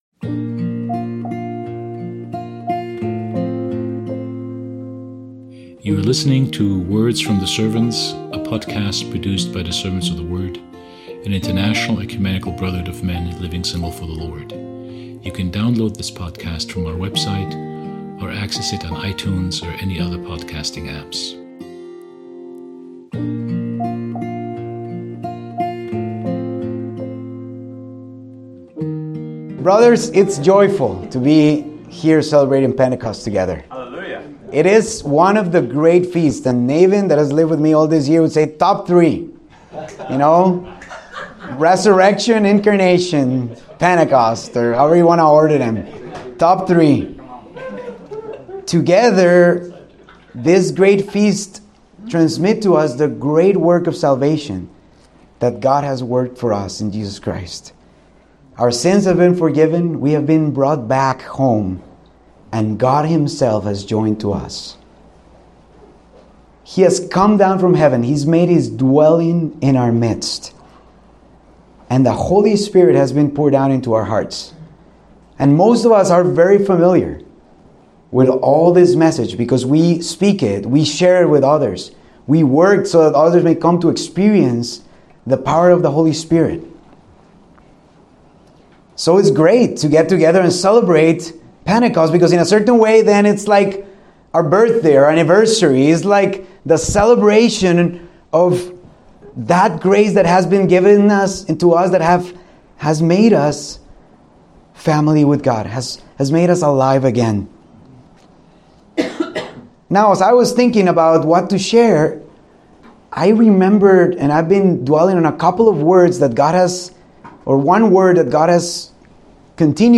Let the Smoke Fill the House | A Pentecost Reflection